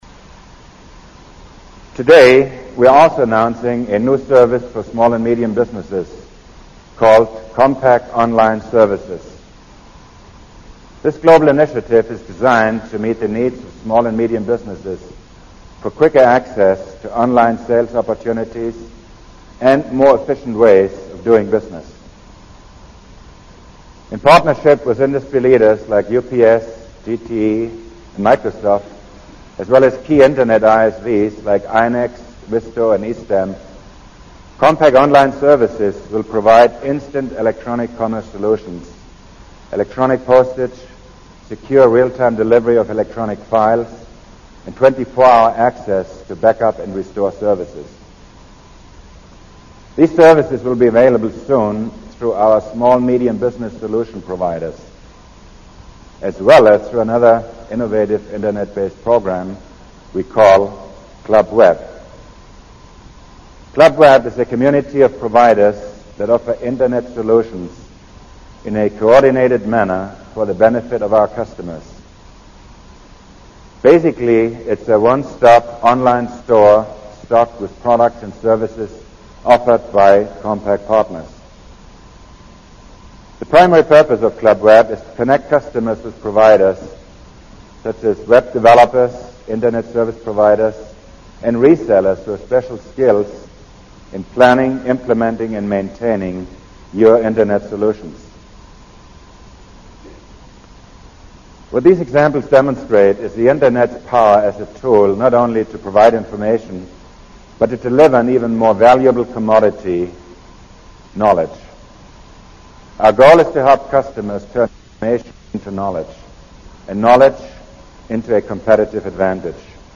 在演讲中他们或讲述其奋斗历程，分享其成功的经验，教人执着于梦想和追求；或阐释他们对于公司及行业前景的独到见解，给人以启迪和思考。